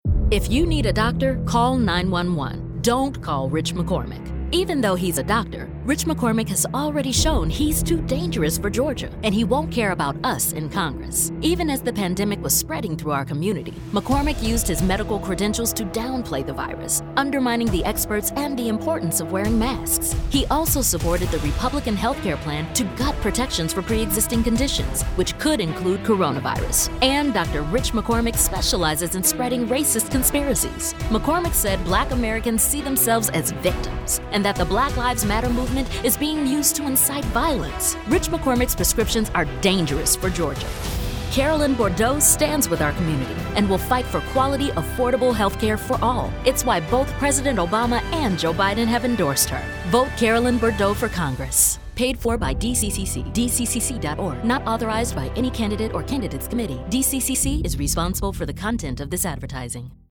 New DCCC ad will reach GA-07 voters on Black serving radio